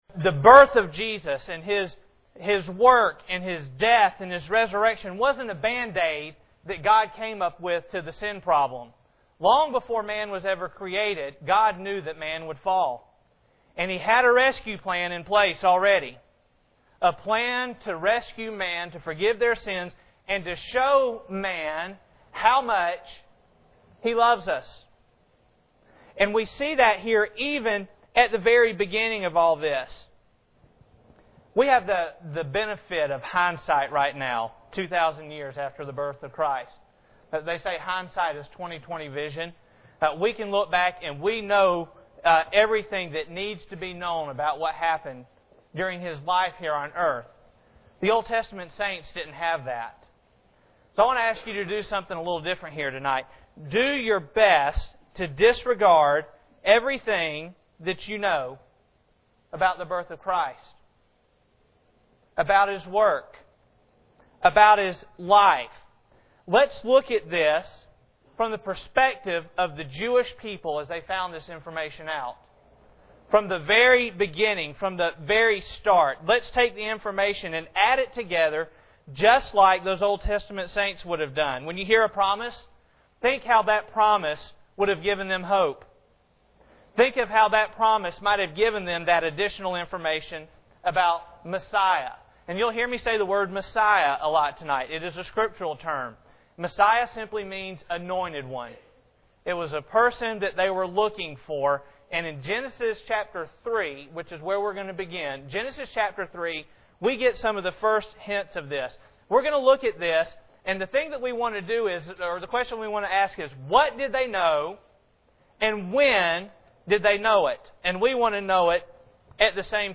Genesis 3:15-21 Service Type: Sunday Evening Bible Text